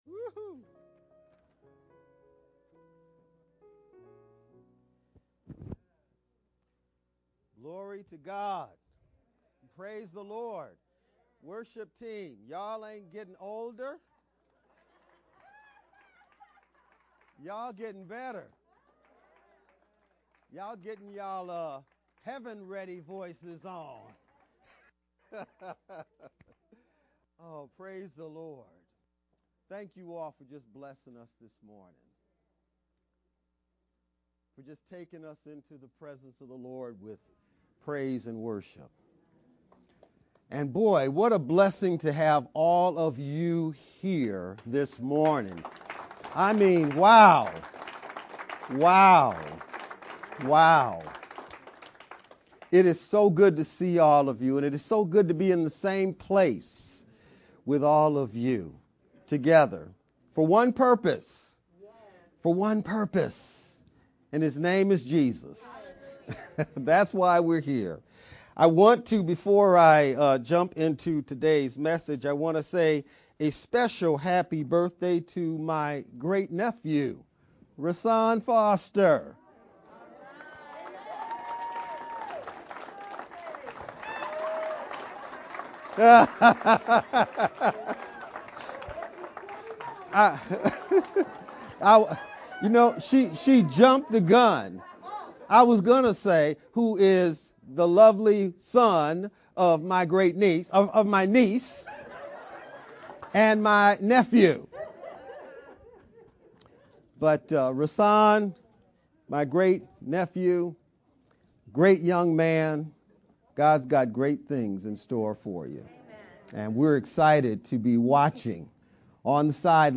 VBCC-Sermon-edited-1-22-sermon-only-mp3-CD.mp3